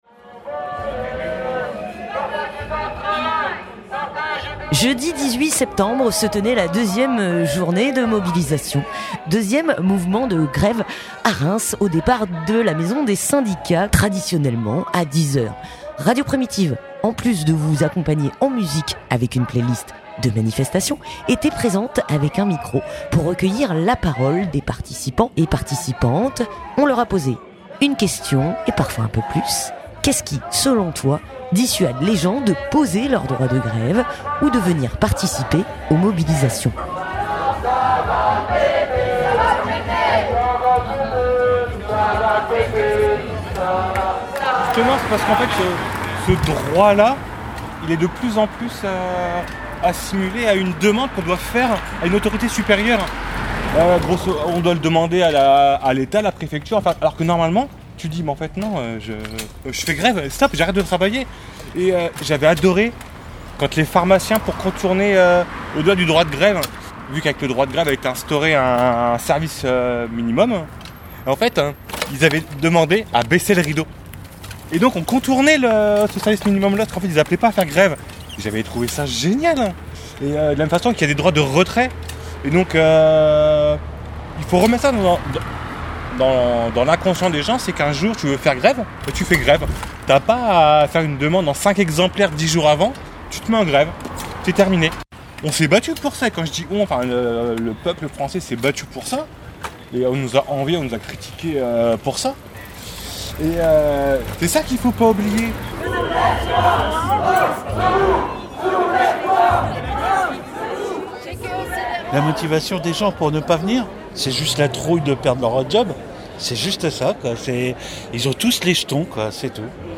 Reportage (13:41)
Radio Primitive a tendu son micro aux manifestant·es pour savoir ce qui selon elles et eux mettez des freins à l'utilisation de leur droit de grève ou à la participation aux manifestations.